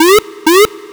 ALARM_Arcade_Reverb_loop_stereo.wav